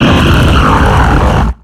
Cri de Regirock dans Pokémon X et Y.